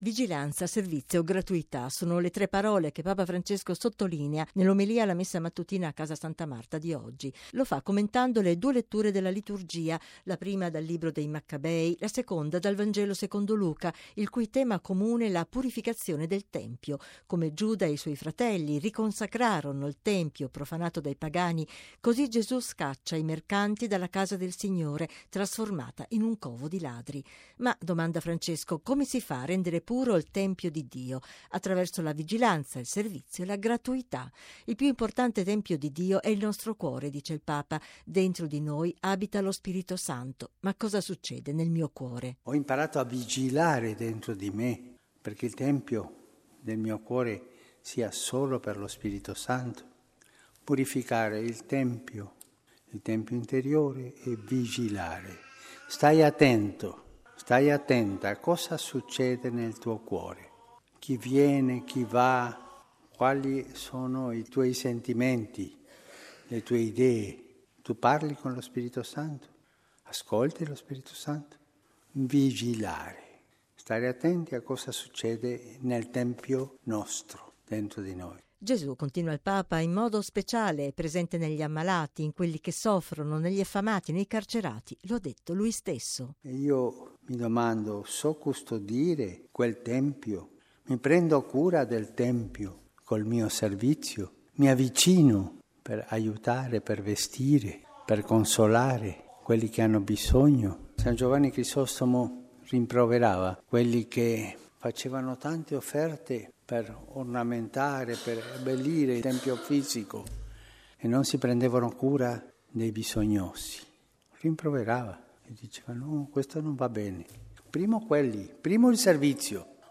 Vigilanza, servizio, gratuità: sono le tre parole che Papa Francesco sottolinea nell’omelia alla messa mattutina a Casa santa Marta di oggi. Lo fa commentando le due letture della Liturgia: la prima dal Libro dei Maccabei, la seconda dal Vangelo secondo Luca, il cui tema comune è la purificazione del tempio.